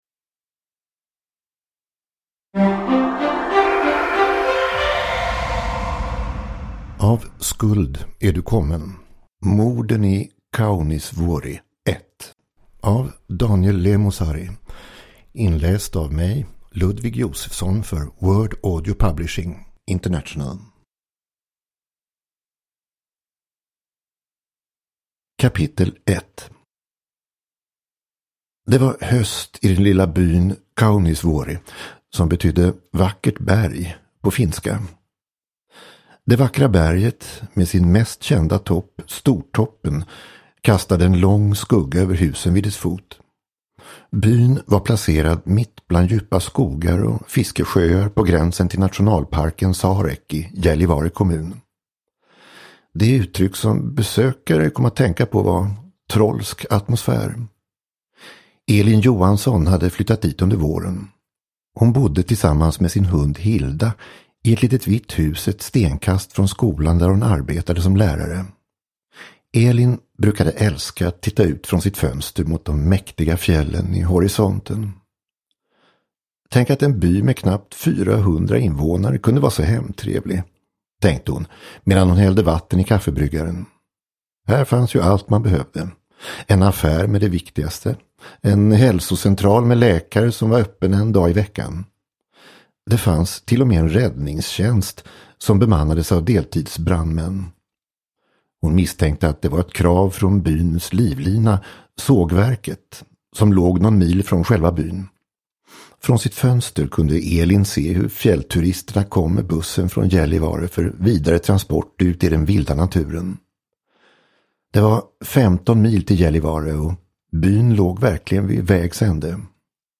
Av skuld är du kommen – Ljudbok